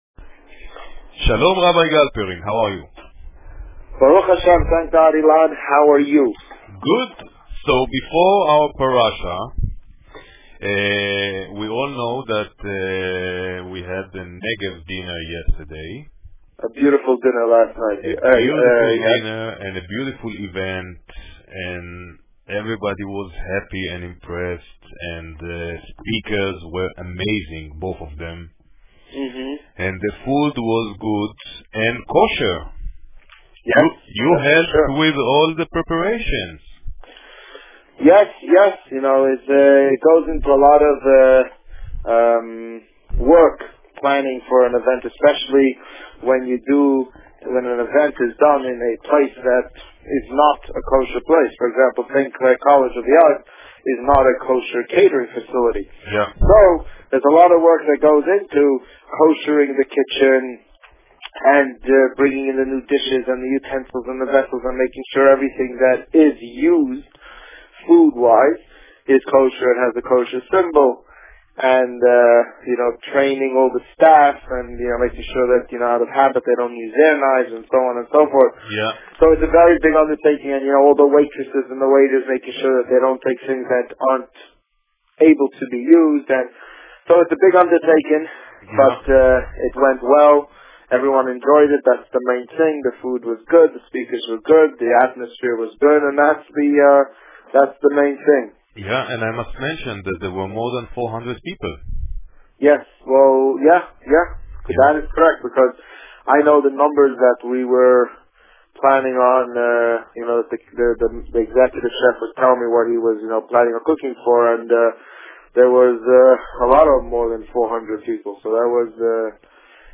The Rabbi on Radio
On June 23, 2011, the Rabbi spoke about the previous night's Negev Dinner and Parsha Korach. Listen to the interview here.